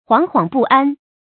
惶惶不安 注音： ㄏㄨㄤˊ ㄏㄨㄤˊ ㄅㄨˋ ㄢ 讀音讀法： 意思解釋： 惶惶：恐懼不安。